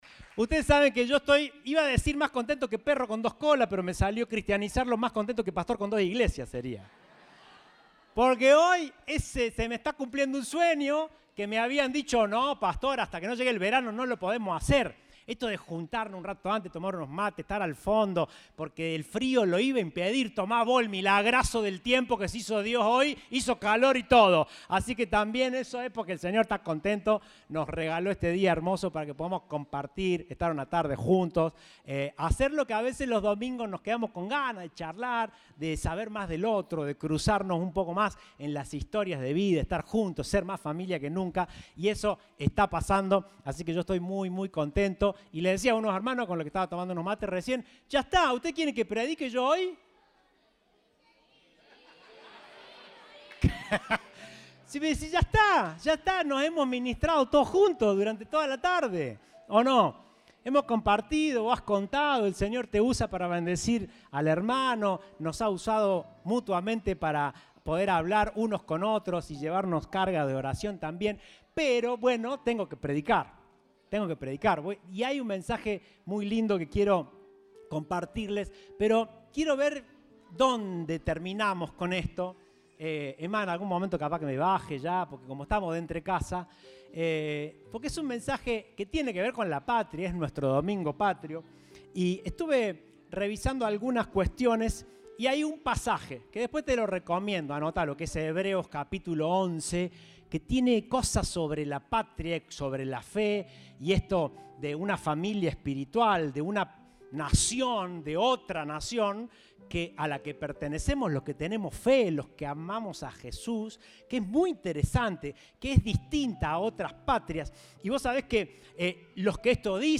Compartimos el mensaje del Domingo 31 de Julio de 2022.